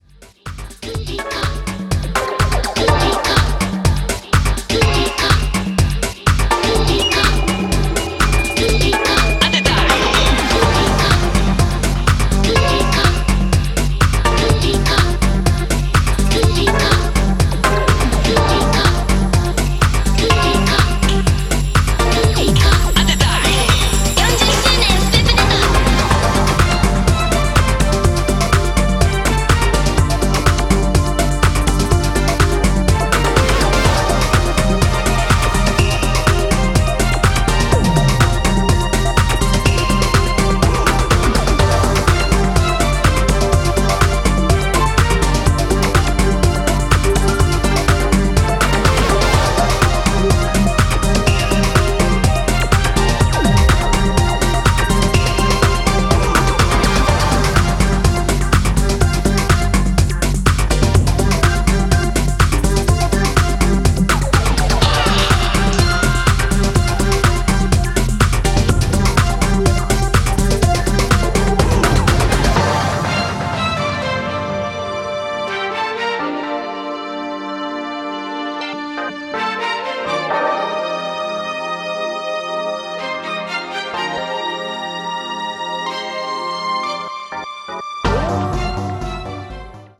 Disco House